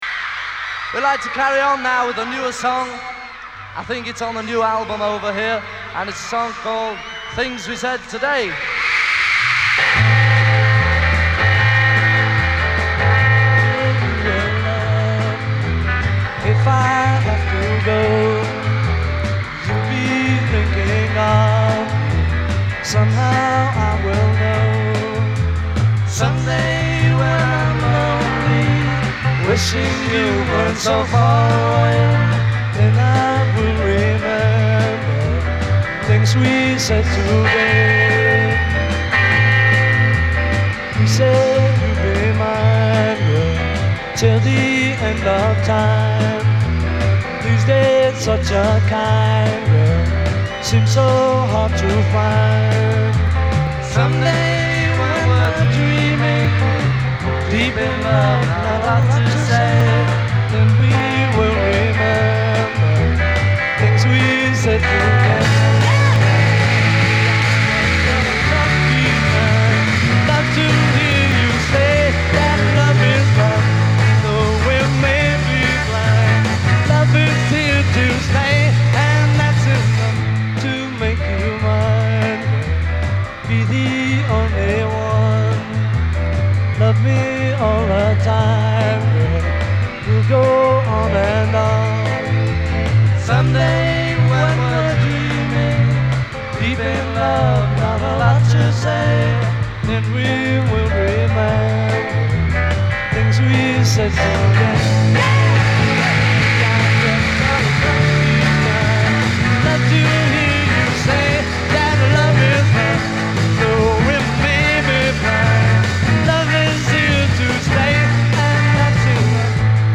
virtually unlistenable
crowd noise